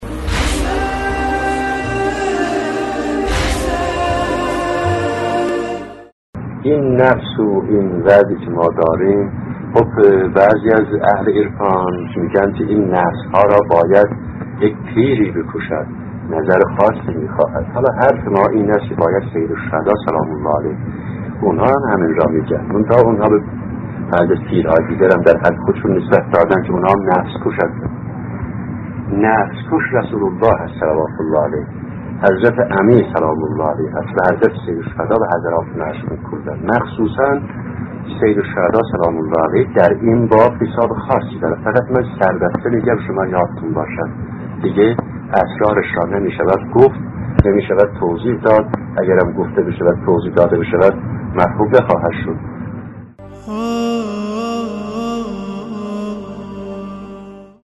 در ادامه، قسمت بیستم این سلسله‌گفتار را با عنوان «کمک سیدالشهدا(ع) در کشتن نفس» می‌شنوید.